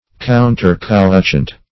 counter-couchant - definition of counter-couchant - synonyms, pronunciation, spelling from Free Dictionary
counter-couchant.mp3